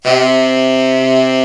Index of /90_sSampleCDs/Giga Samples Collection/Sax/HARD SAX
TENOR HARD C.wav